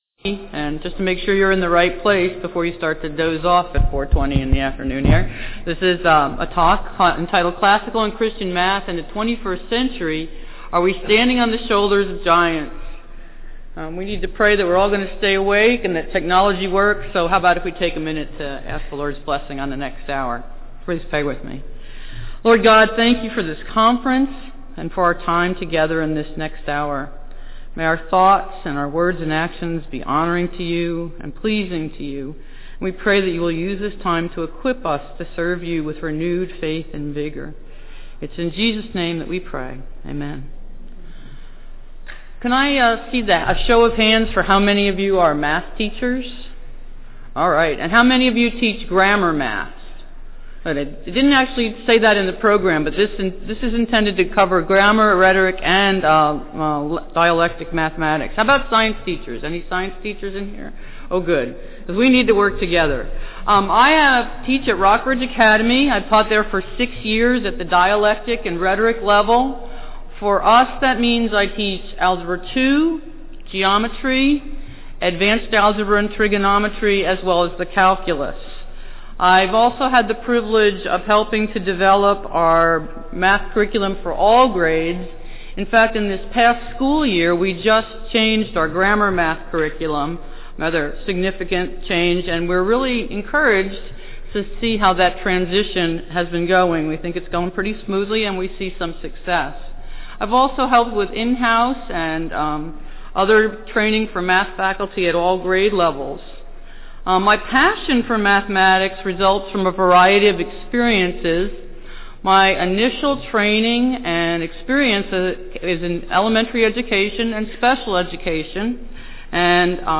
2009 Workshop Talk | 0:14:27 | All Grade Levels, Math
The Association of Classical & Christian Schools presents Repairing the Ruins, the ACCS annual conference, copyright ACCS.